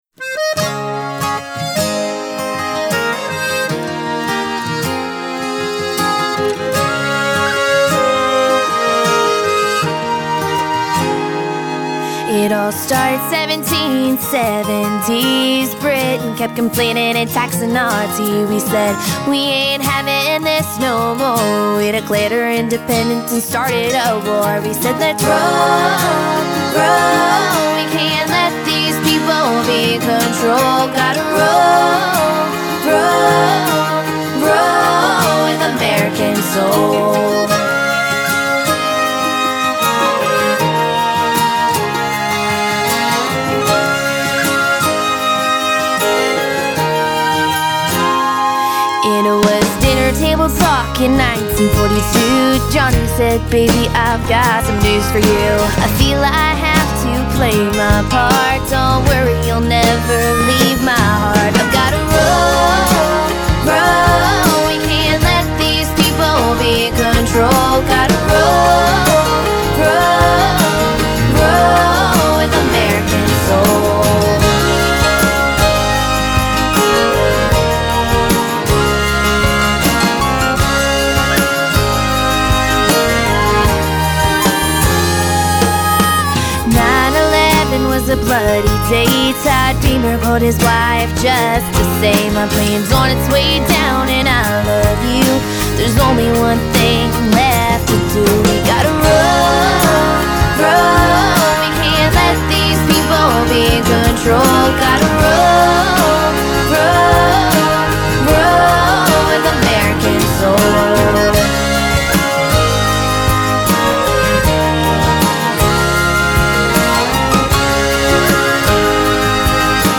country pop band from Fredericksburg